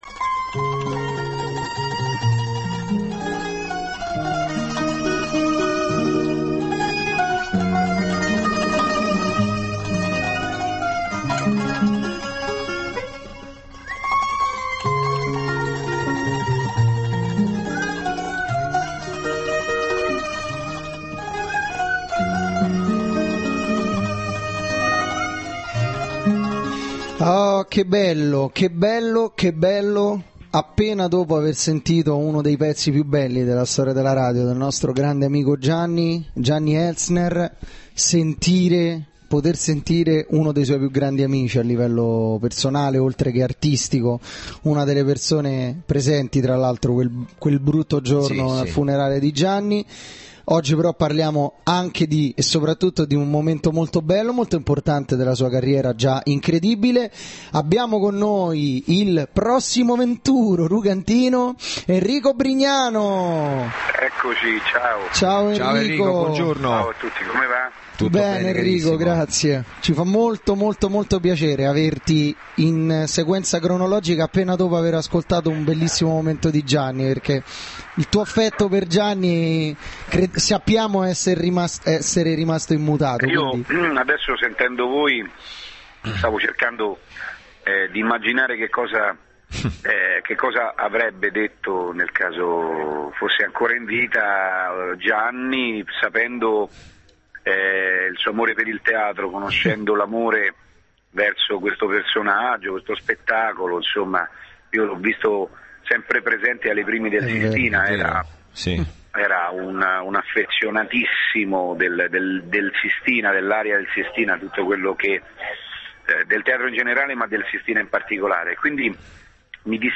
Intervento telefonico Enrico Brignano